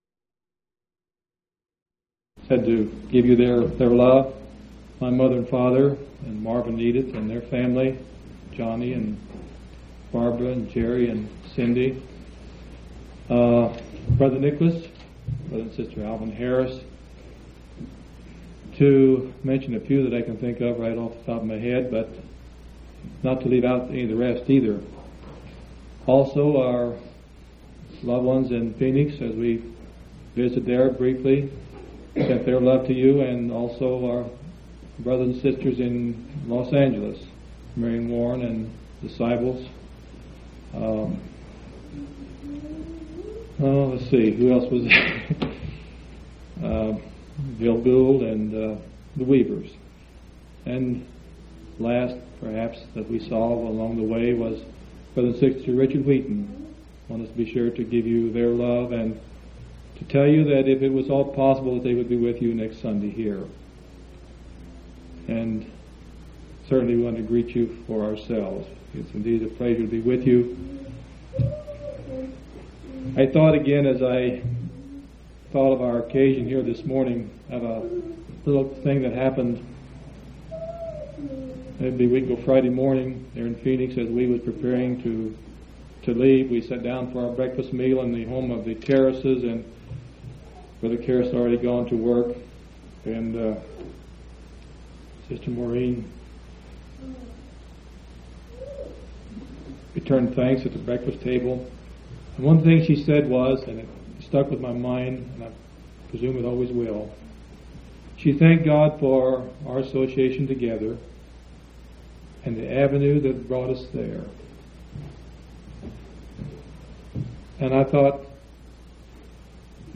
9/30/1984 Location: Grand Junction Local Event